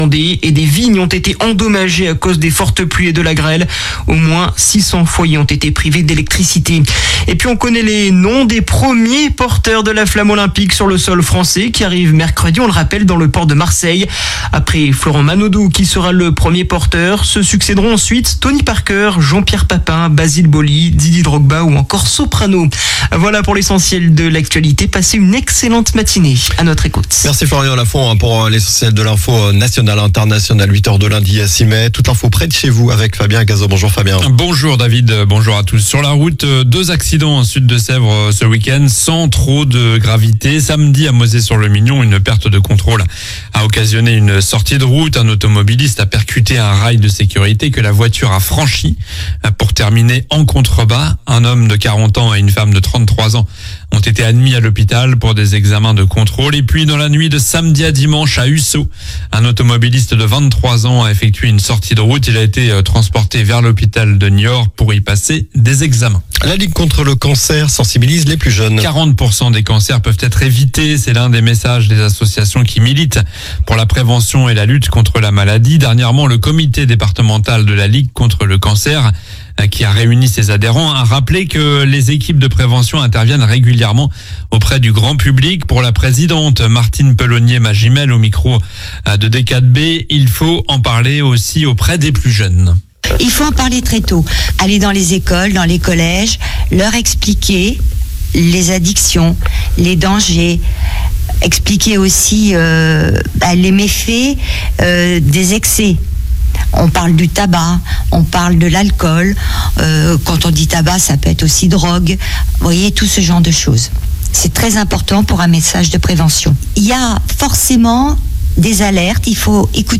Journal du lundi 06 mai (matin)